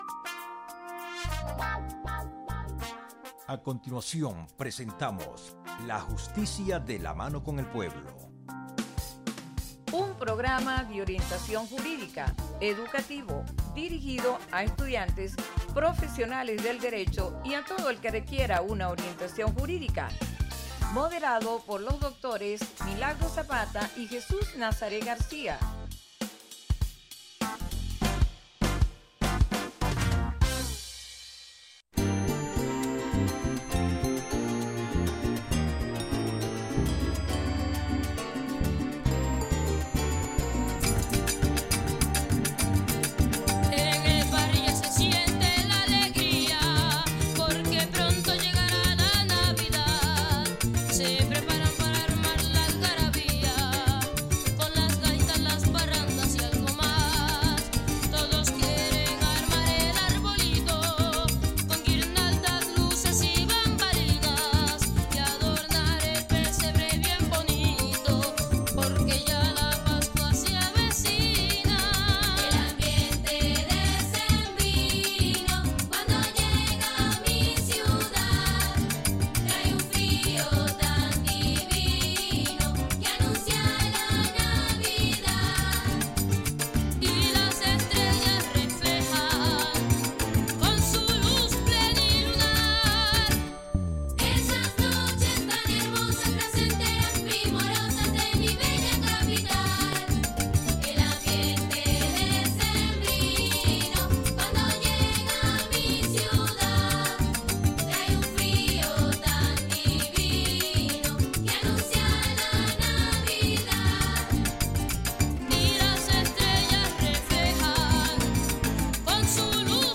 Programa grabado
Programa de orientación jurídica con invitados especiales, comentarios y buena música.